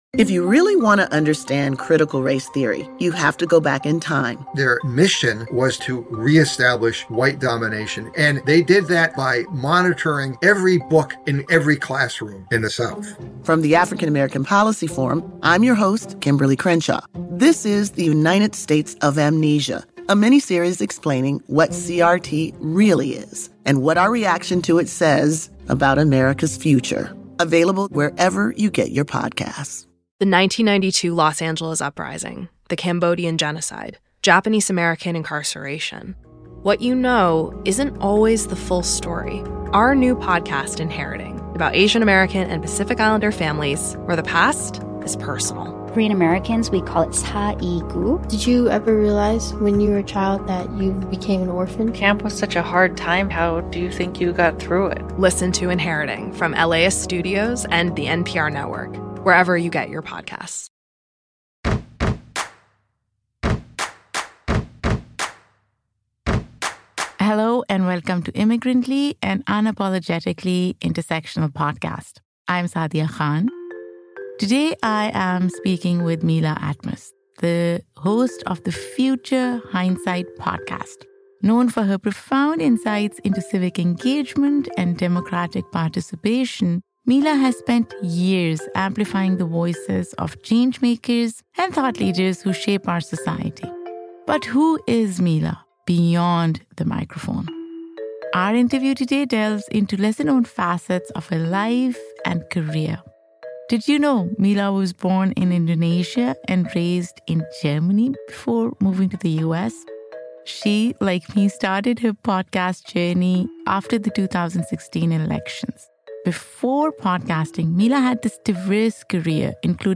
This conversation delves into the lesser-known aspects of her life and career.